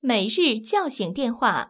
ivr-for_daily_wakeup_call.wav